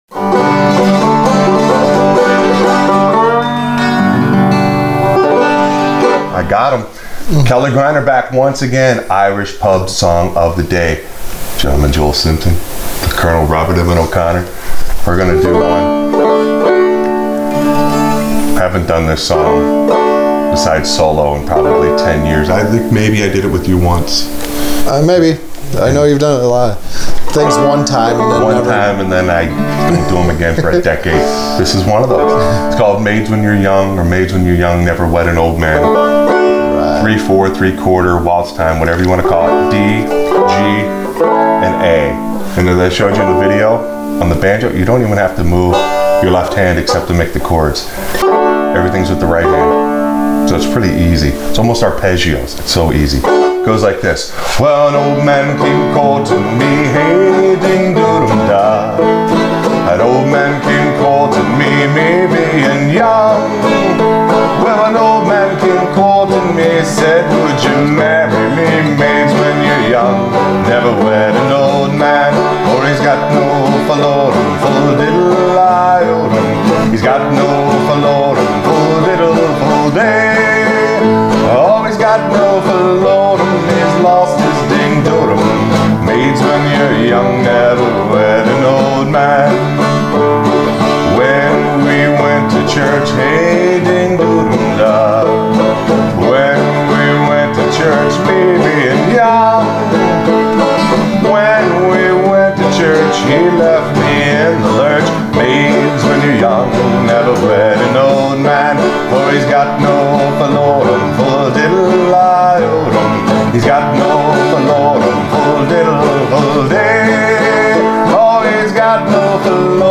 Irish Pub Song Of The Day – Maids When You’re Young – Accompaniment for Frailing Banjo